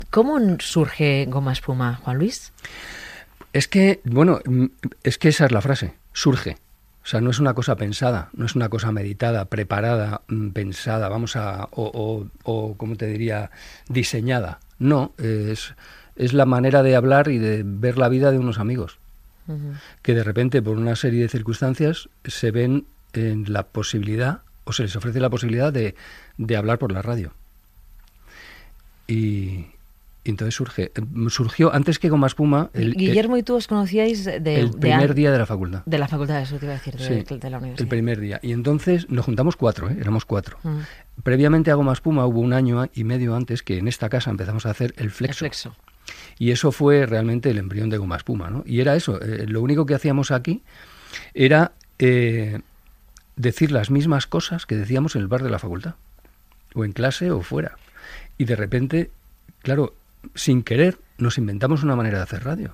Secció "Gato Pardo" amb una entrevista a Juan Luis Cano que explica com va sorgir Gomaespuma dins del programa "El flexo" de Radio Madrid
Entreteniment